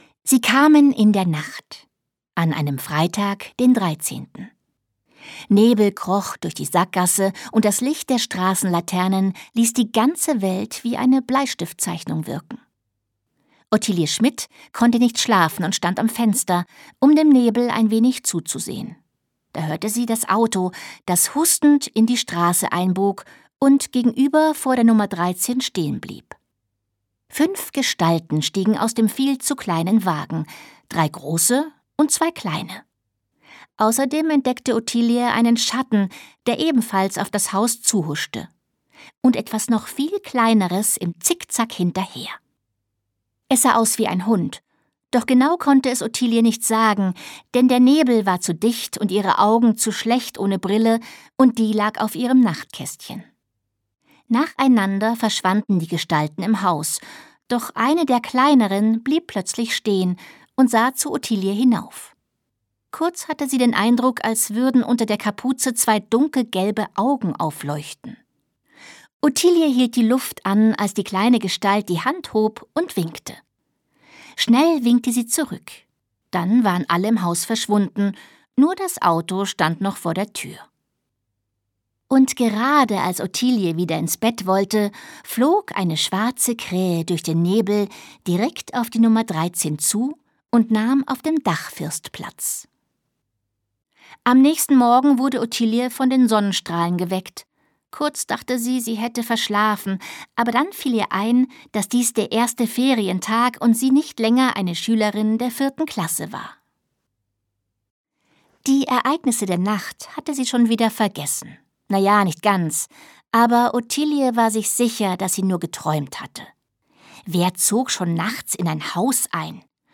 Sprecherin: Sabine Bohlmann
gekürzte Autorenlesung
Das Hörbuch ist extrem gut gesprochen von der Autorin selbst. Ja, sie weiß, wie sie die Charaktere anlegen muss.